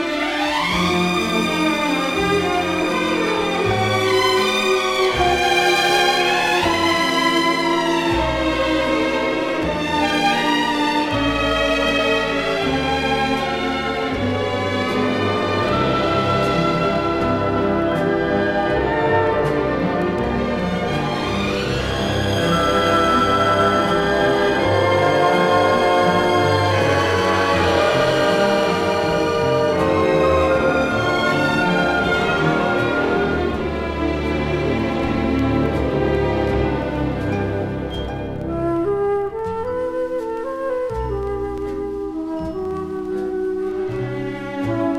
耳馴染み良く、豊かに響く演奏に心動かされる良盤です。